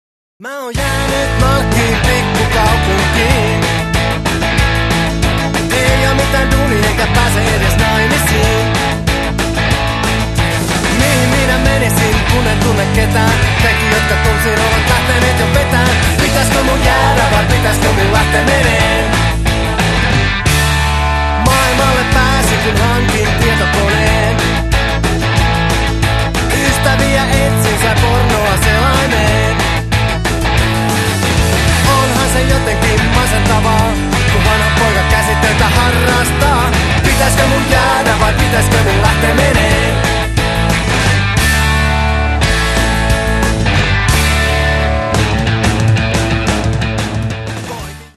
(Compressed/Mono 330kb)